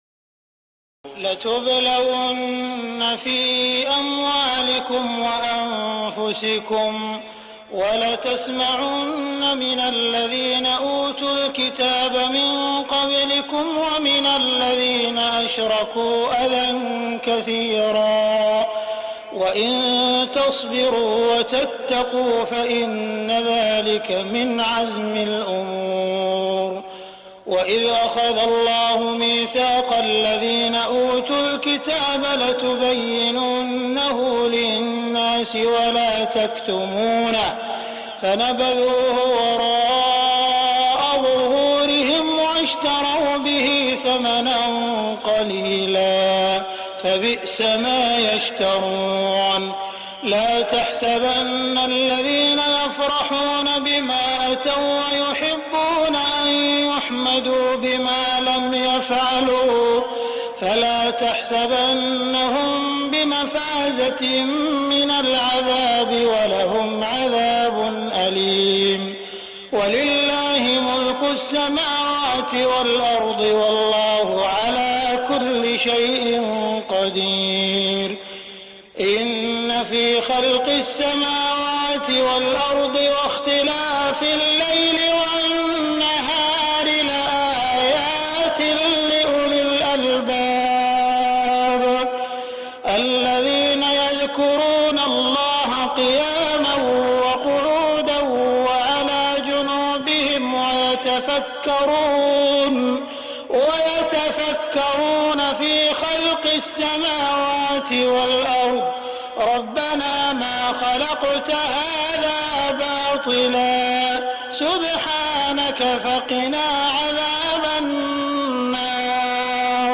تهجد ليلة 24 رمضان 1418هـ من سورتي آل عمران (186-200) و النساء (1-28) Tahajjud 24 st night Ramadan 1418H from Surah Aal-i-Imraan and An-Nisaa > تراويح الحرم المكي عام 1418 🕋 > التراويح - تلاوات الحرمين